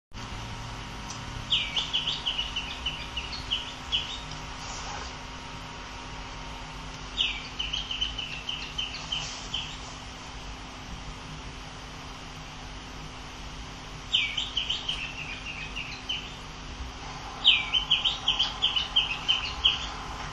Bailarín Oliváceo (Schiffornis virescens)
Nombre en inglés: Greenish Schiffornis
Localidad o área protegida: Parque Nacional Iguazú
Condición: Silvestre
Certeza: Vocalización Grabada